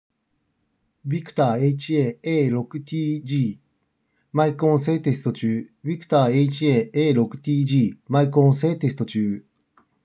マイク性能はそれなりにいい
多少こもって聞こえますが、音声もしっかり聞き取れるので5,000円以下って考えるとまともな作りになっています。